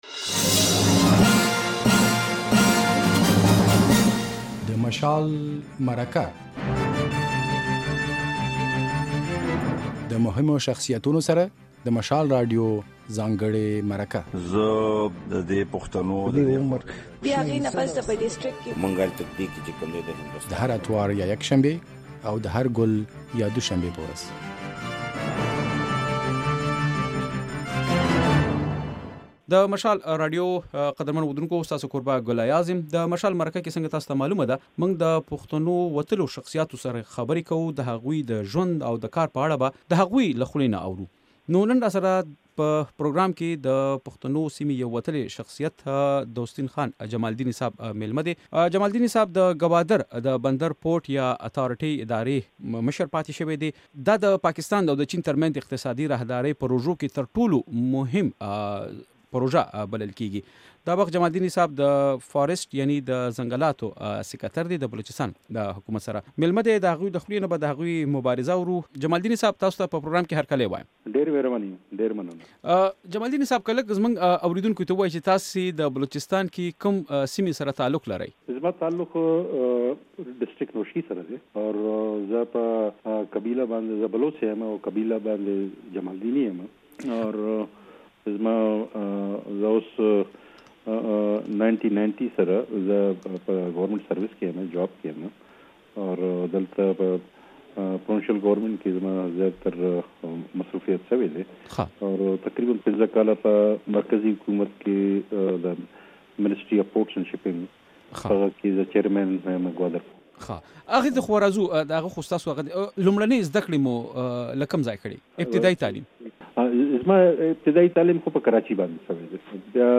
په مشال مرکه کې د بلوچستان ځنګلاتو محکمې سېکرېټري دوستين خان جمالديني مېلمه دی.